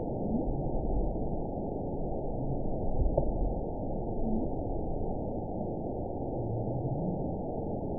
event 919951 date 01/29/24 time 03:36:18 GMT (1 year, 4 months ago) score 9.22 location TSS-AB08 detected by nrw target species NRW annotations +NRW Spectrogram: Frequency (kHz) vs. Time (s) audio not available .wav